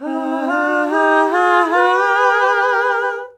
HAHAHAHAA.wav